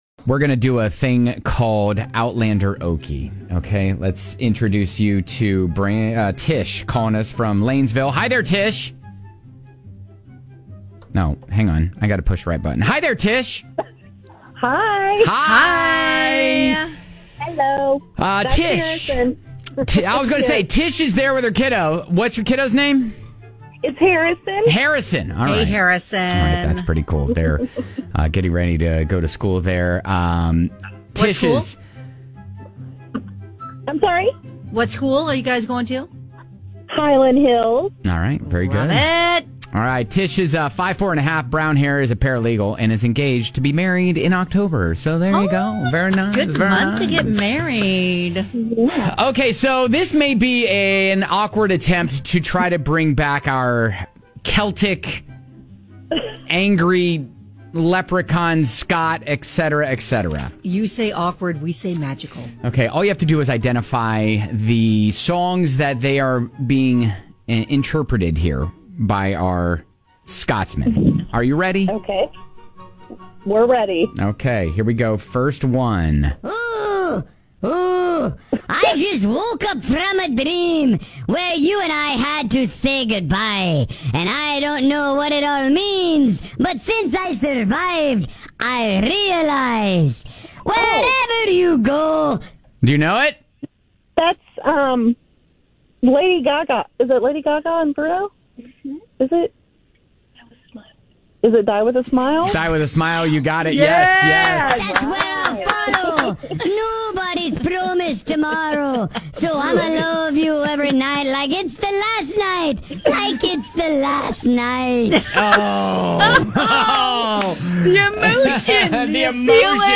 Guess the song, sung (or interpreted) by an angry Scot for tickets to Outlander in Concert: Echoes Through the Highlands,” at The Louisville Palace on October 13th!